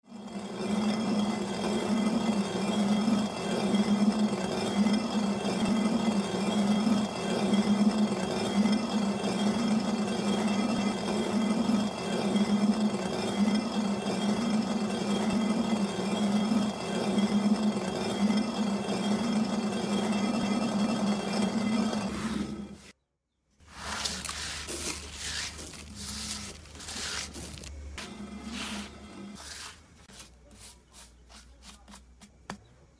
Tiếng Cối đá Xay bột, Xay gạo… ngày xưa
Thể loại: Tiếng động
Description: Tiếng cối đá xay bột, tiếng cối đá, xay gạo, xay nếp, xay hạt, âm thanh lạo xạo, ken két, rào rào... khi hai phiến đá mài nghiến vào nhau, nghiền nát từng hạt gạo, hạt ngô, hạt đậu nành... thành lớp bột mịn để làm bánh, làm sữa đậu nành. Đây là tiếng lao động cần mẫn, của bàn tay con người hòa cùng tiếng lạch cạch, xoay vòng chậm rãi mà bền bỉ.
tieng-coi-da-xay-bot-xay-gao-ngay-xua-www_tiengdong_com.mp3